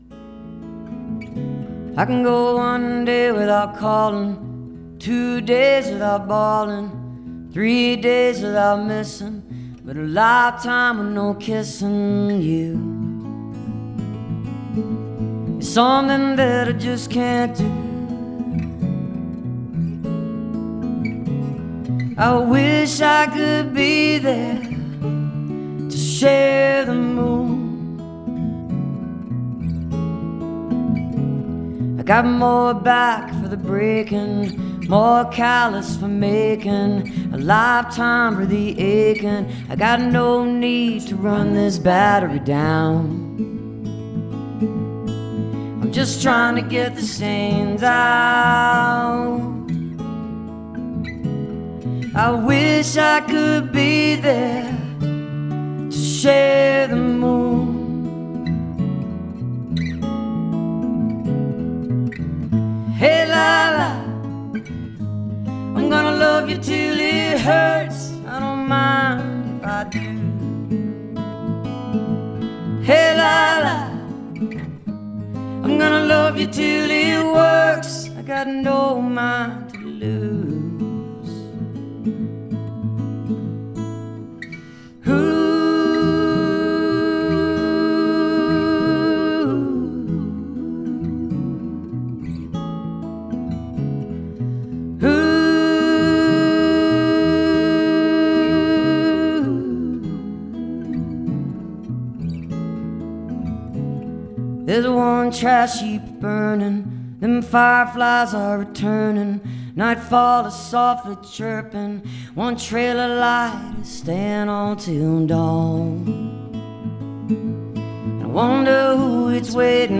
(recorded from webcast)